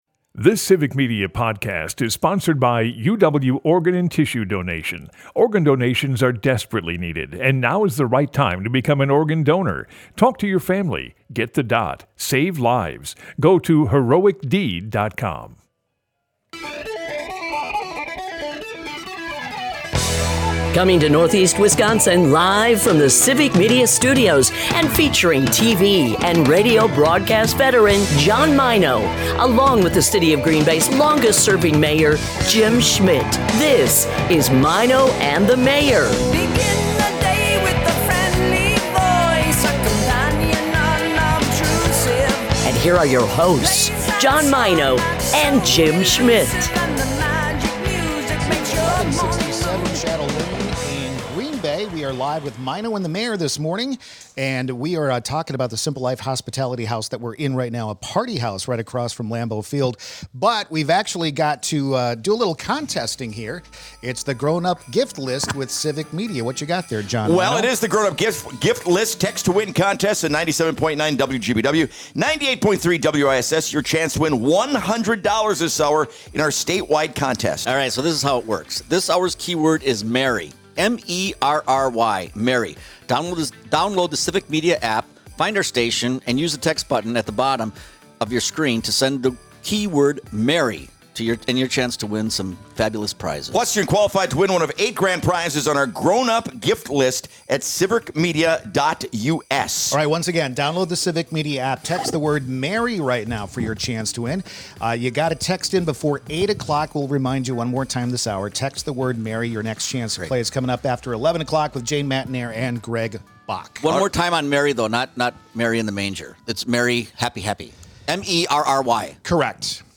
Another hour from our Simple Life Hospitality Party House across from Lambeau Field.